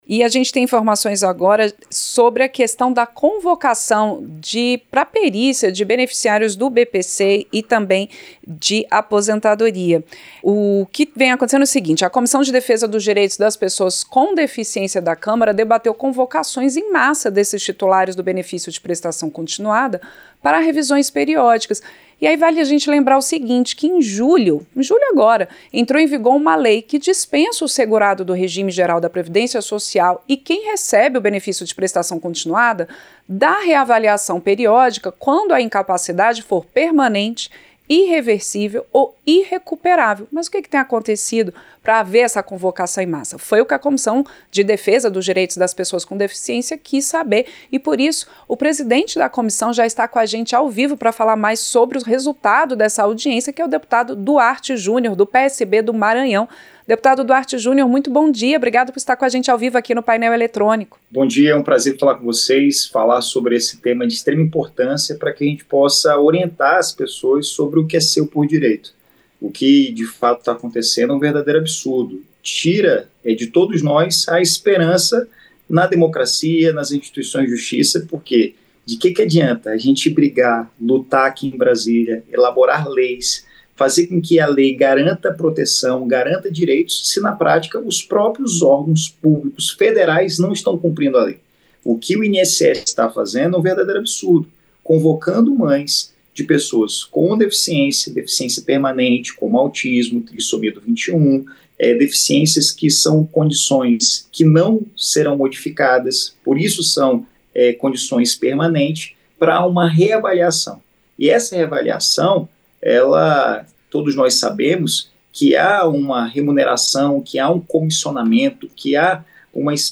Entrevista - Dep. Duarte Jr. (PSB-MA)